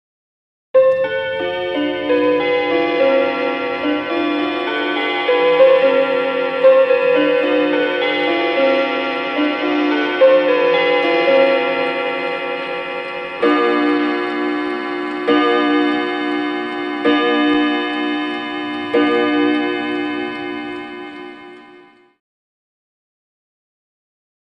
Clock Chimes Rings|Chime|4 Hour | Sneak On The Lot
Clock Chime; Mantel Clock Musical Chimes And Strikes Four.